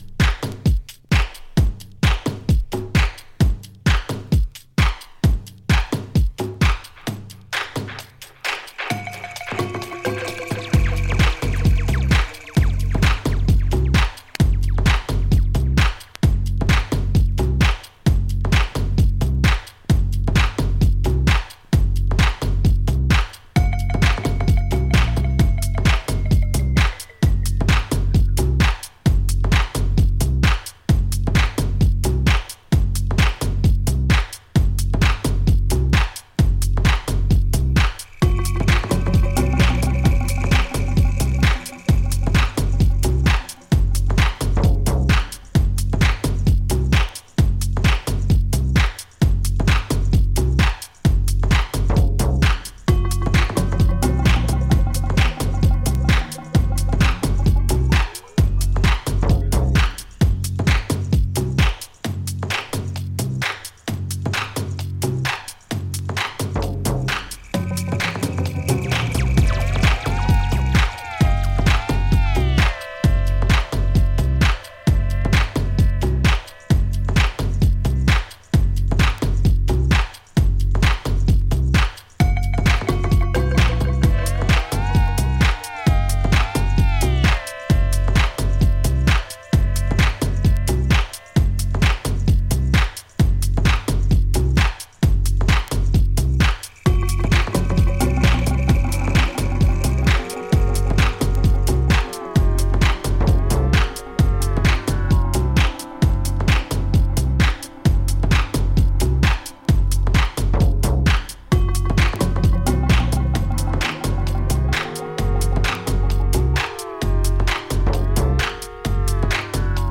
ユーモラス、かつ黒い狂気が充満している傑作です。